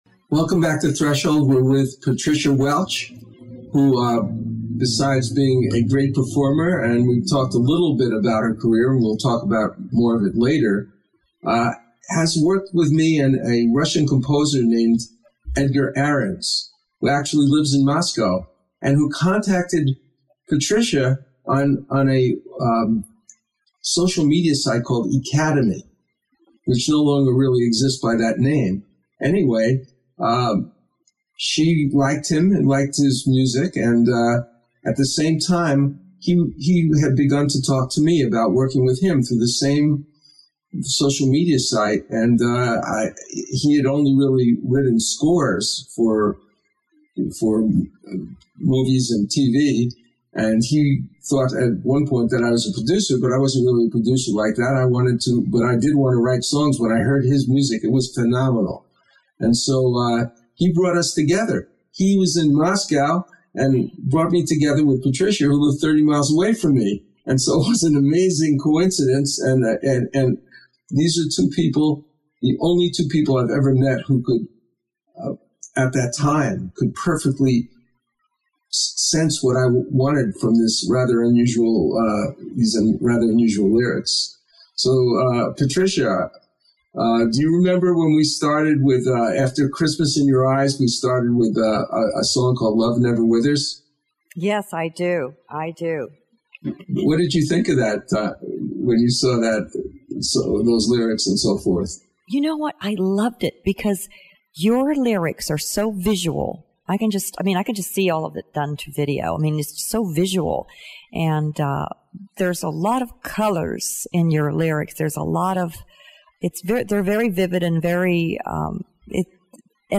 Talk Show Episode
It is a richly musical, dramatic program.